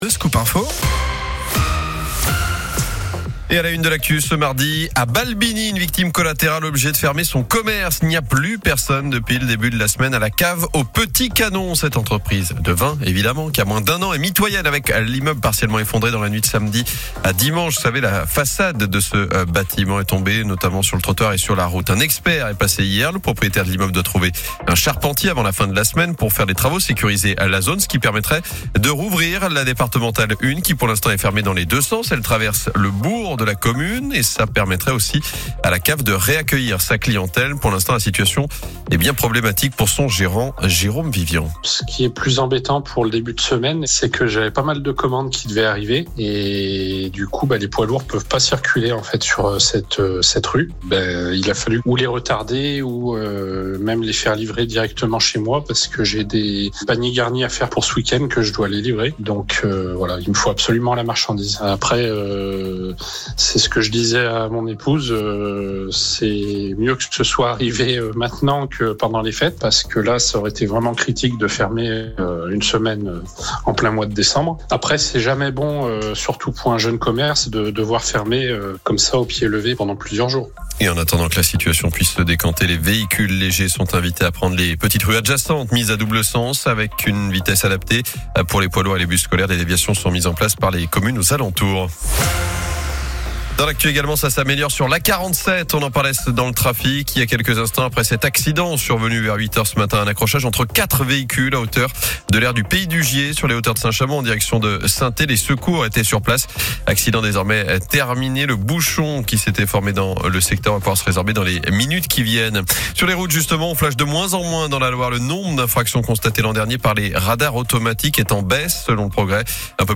RADIO SCOOP INTERVIEW